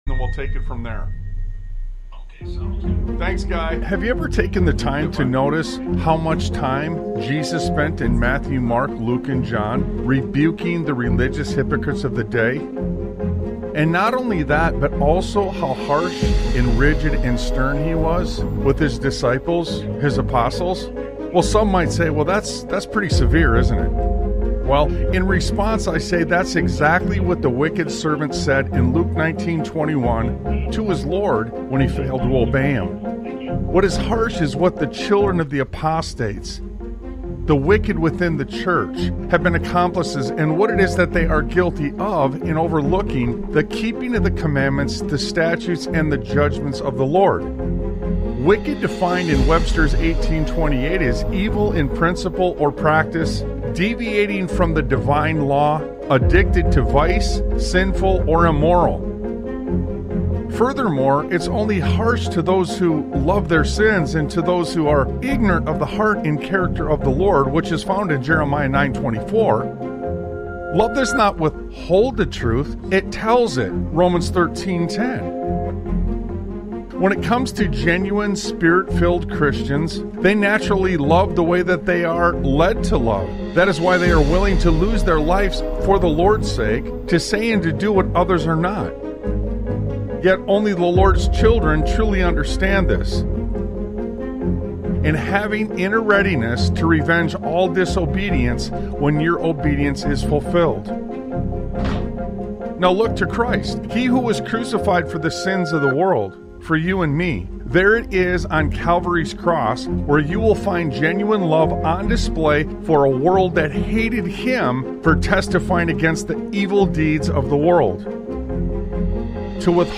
Radio Broadcast Analysis • April 08